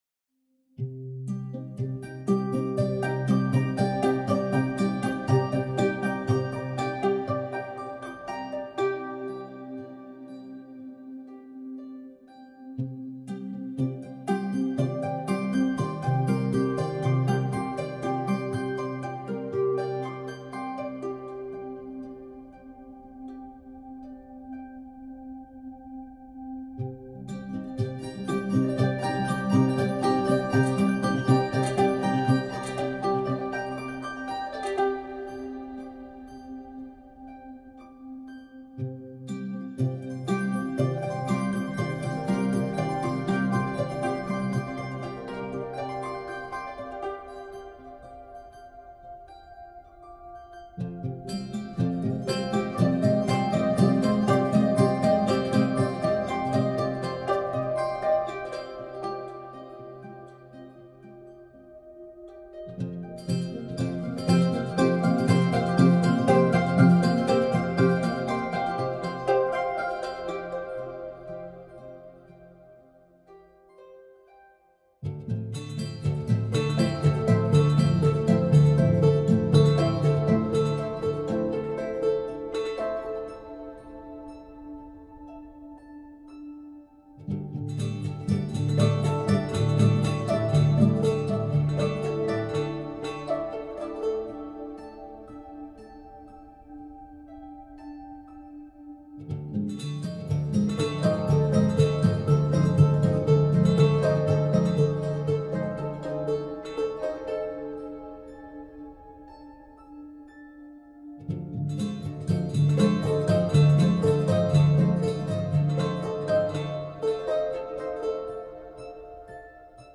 without viola